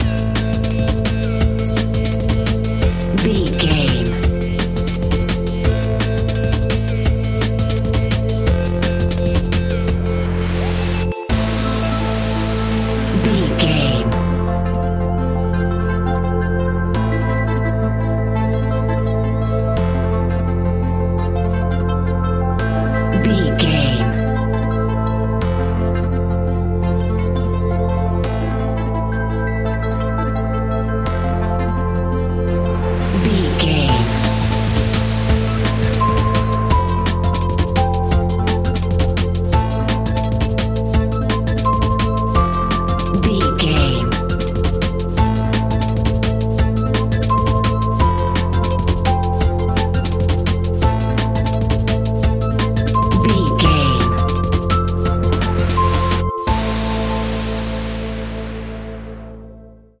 Aeolian/Minor
Fast
aggressive
groovy
futuristic
frantic
drum machine
synthesiser
electronic
sub bass